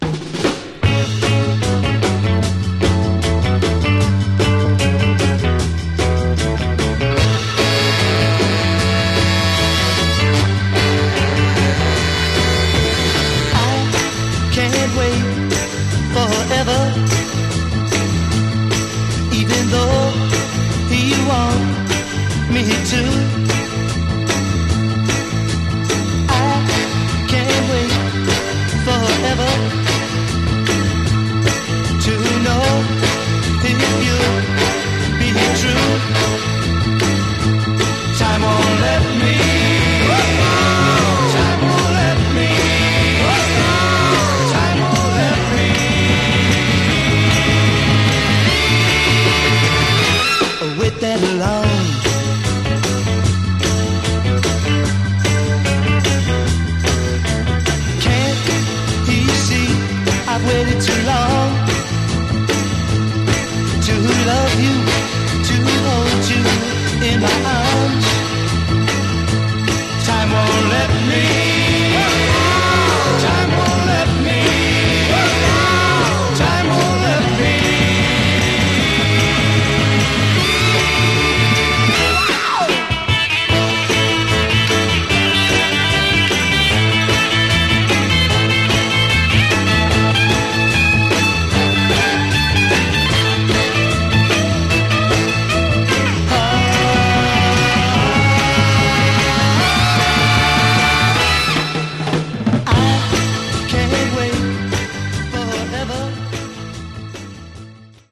Genre: Horn Rock